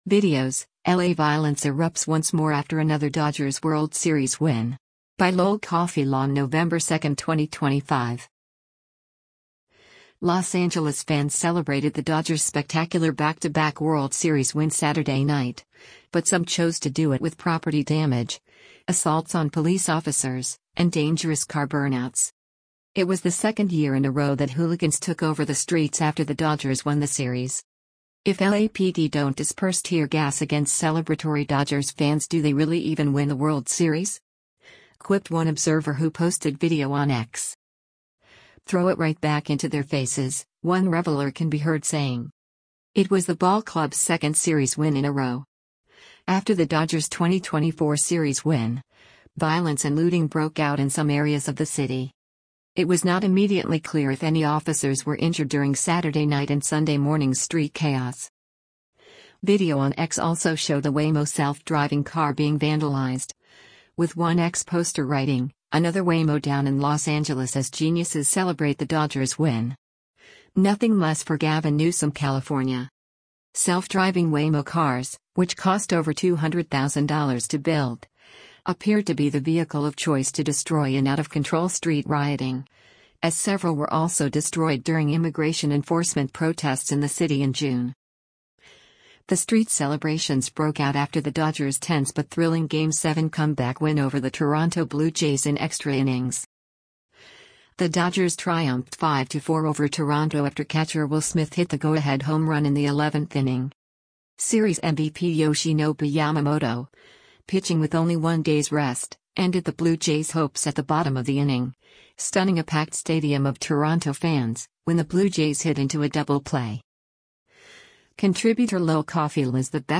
A Los Angeles Dodgers fan lights fireworks on the street during celebrations in Echo Park
“Throw it right back into their faces,” one reveler can be heard saying.